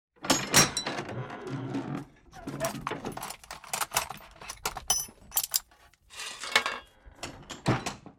shed_1.ogg